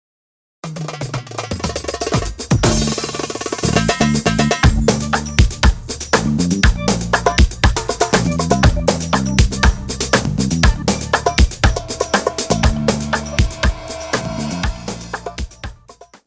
• Type : Instrumental
• Bpm : Allegro
• Genre : Oriental Grooves